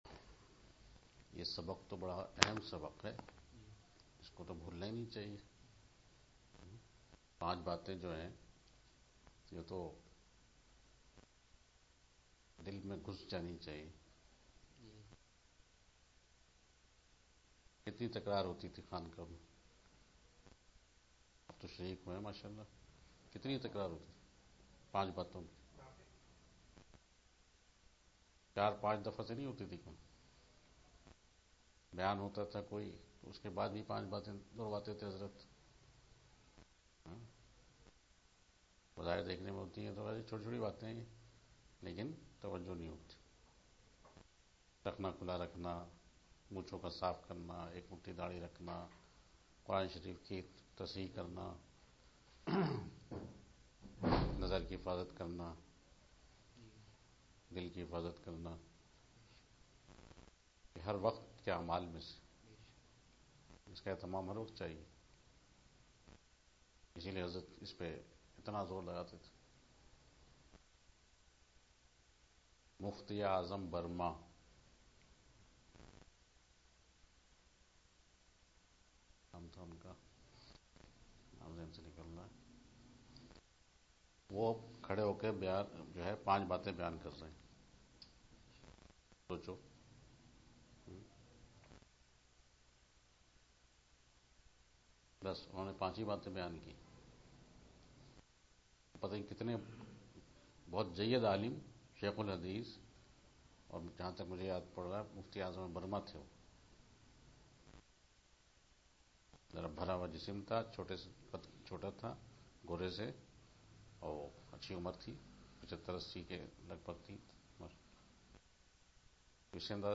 Majlis of January 20